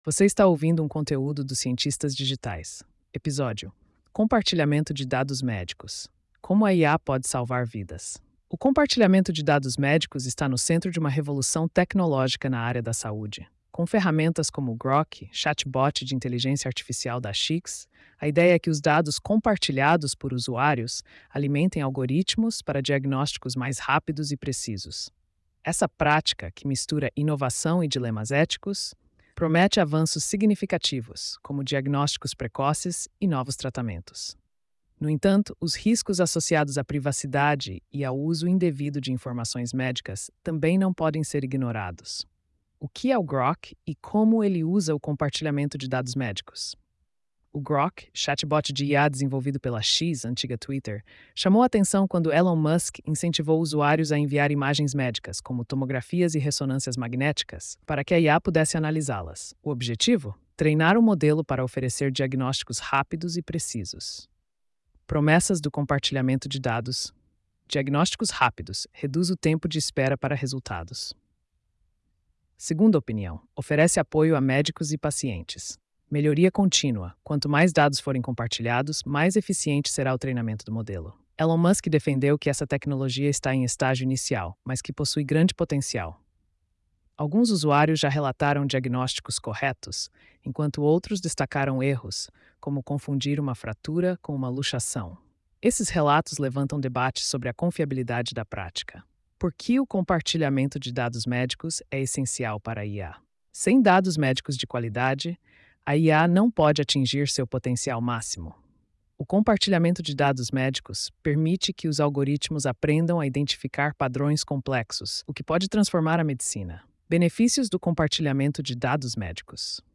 post-2597-tts.mp3